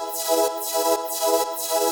SaS_MovingPad02_125-E.wav